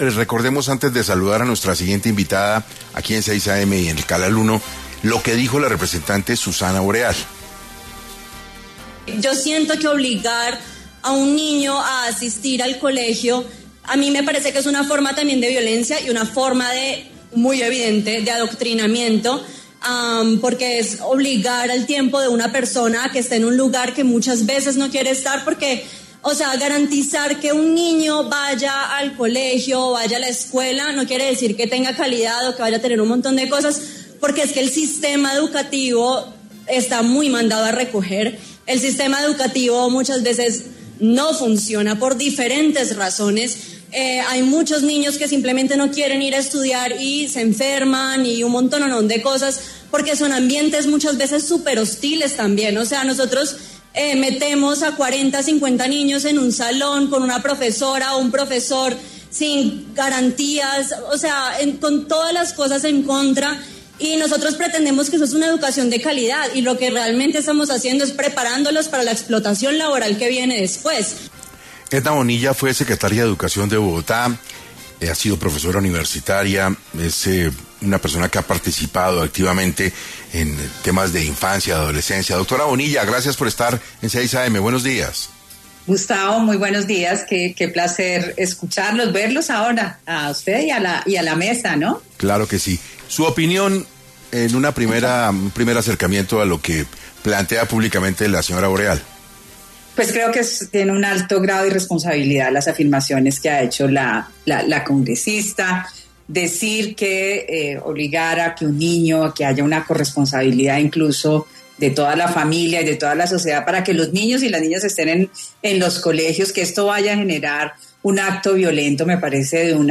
En Caracol Radio estuvo Edna Bonilla, exsecretaria de Educación de Bogotá